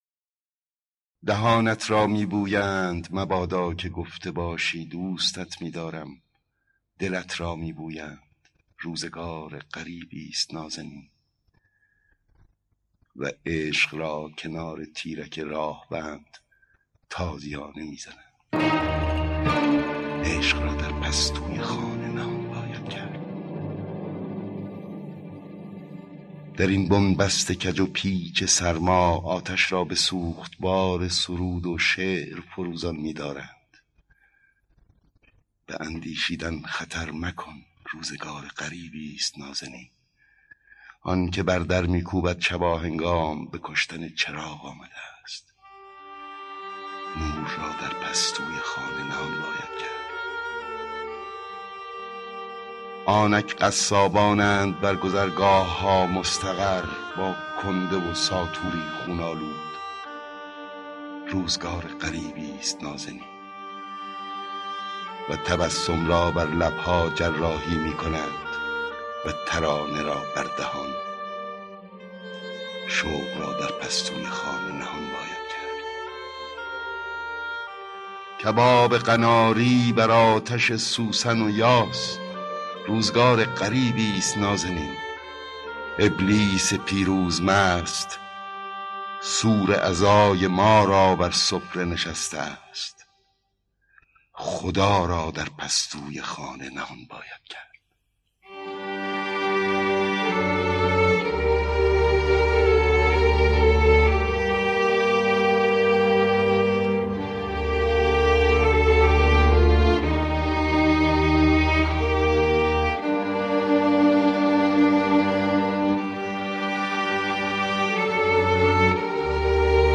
دکلمه شعر در این بن بست با صدای احمد شاملو